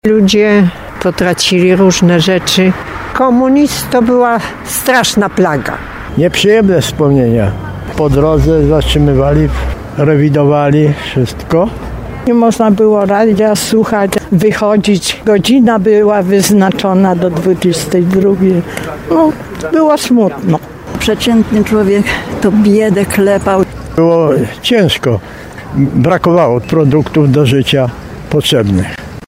Mieszkańcy Tarnowa, którzy doskonale pamiętają tamte lata, podkreślają, że to były trudne i smutne czasy.
'Kontynuujemy to dzieło’ Tagi: Tarnów Instytut Pamięci Narodowej sonda stan wojenny represje